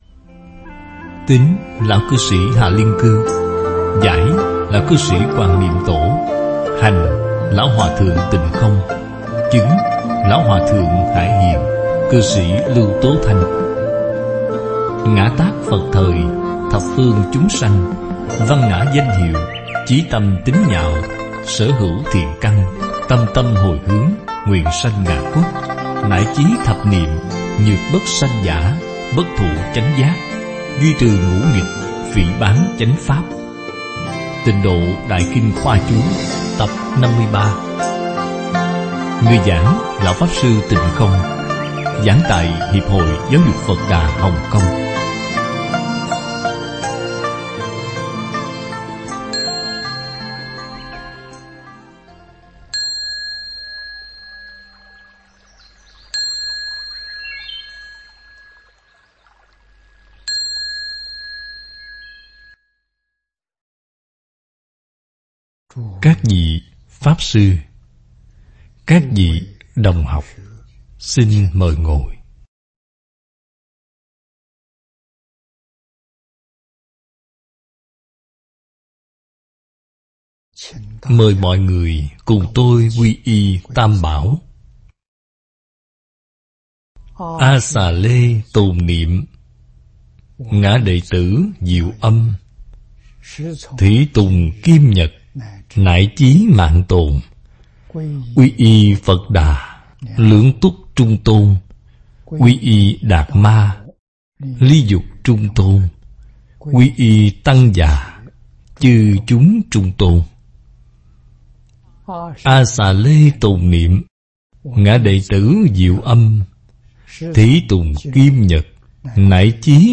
Tịnh Độ Đại Kinh Khoa Chú giảng lần thứ 4 năm 2014 - Tập 52 - Bài giảng Video